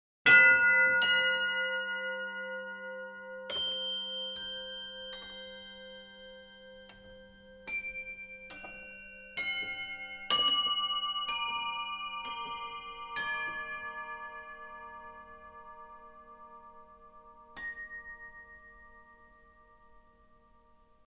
Das Toy Piano – Saucer Bell …
Hier werden Metallstangen mit Balkelit-Hämmern angeschlagen.
Bei späteren Modellen wurden tellerförmige Mini-Gongs angeschlagen, die einen Klang, ähnlich dem Geläut einer Wohnzimmer-Standuhr erzeugen (diese Aussage betrifft jetzt wieder das Standklavier, nicht den Volksempfänger).